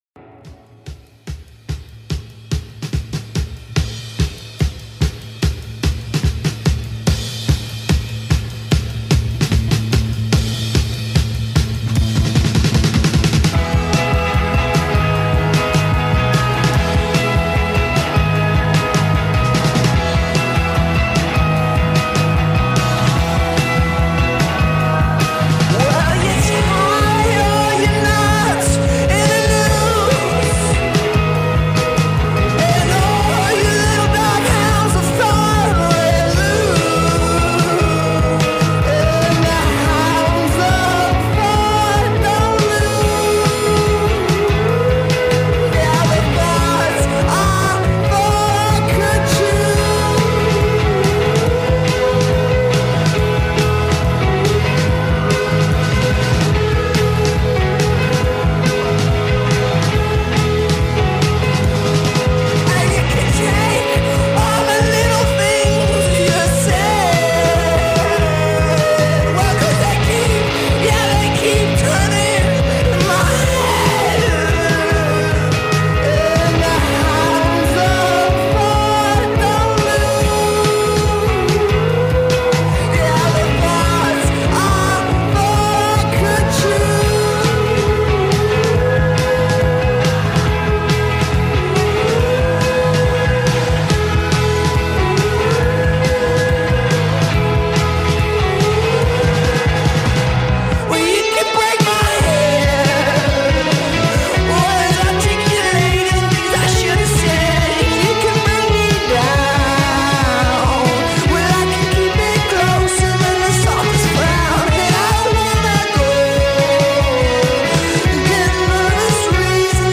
lo-fi indie-rock band